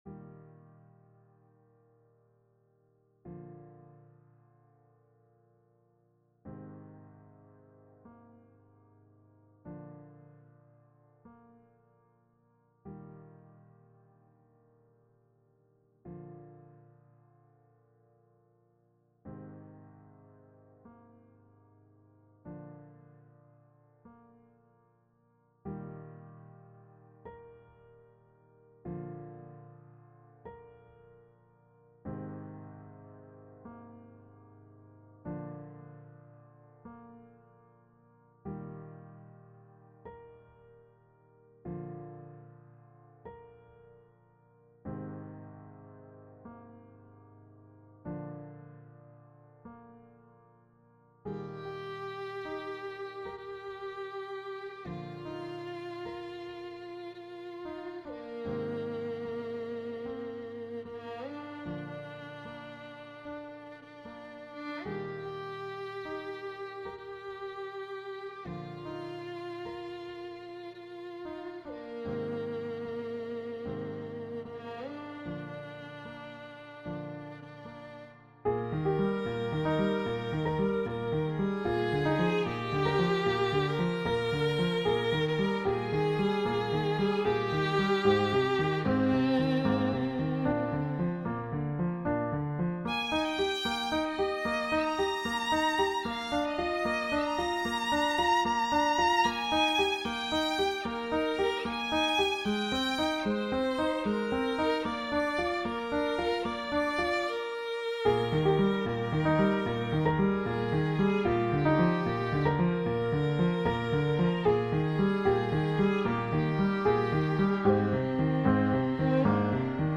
Audio recorded with instrument samples Sheet music here Read More